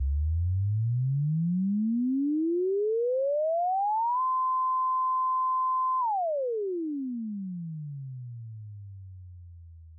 ピッチが上がって下がるサイン波サイン波のスペクトログラム:ピッチが高くなっていくにつれ線が上へ伸び、下がると下へ下り、山のような形になる。
P-ENV-spectrogram-sine.mp3